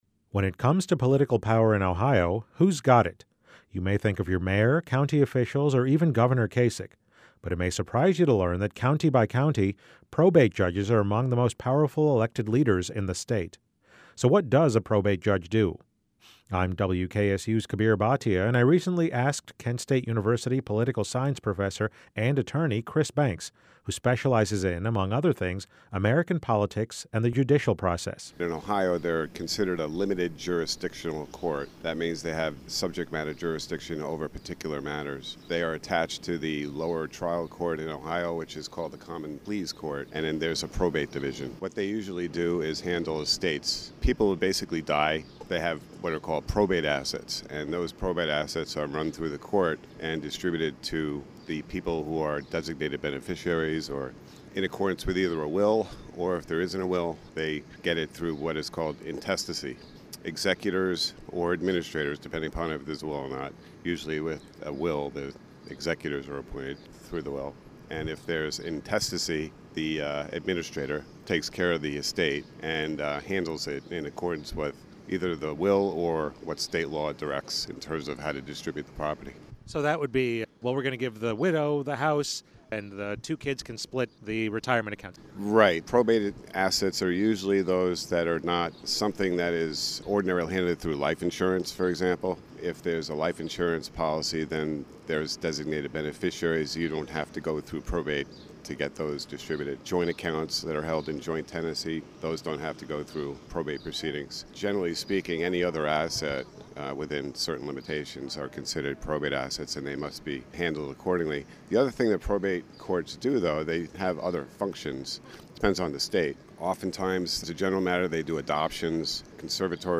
WKSU Radio runs series of news reports on the role of the Probate Court